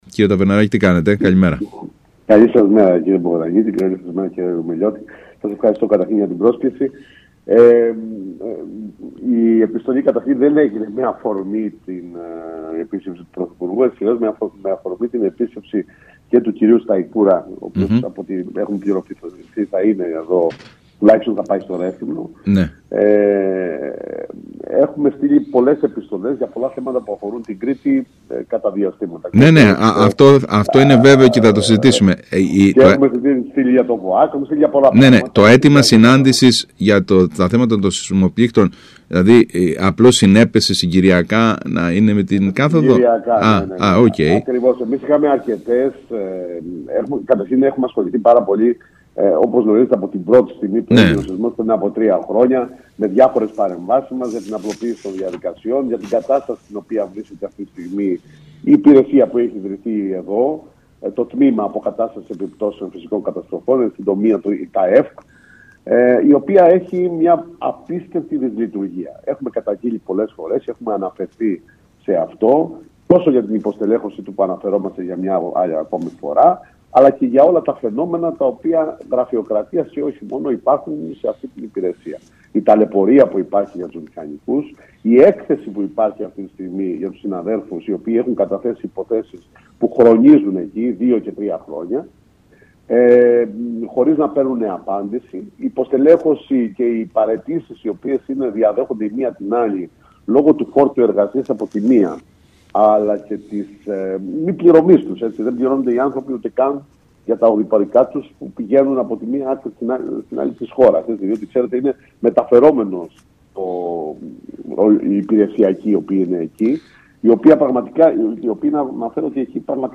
μιλώντας στον ΣΚΑΪ Κρήτης 92,1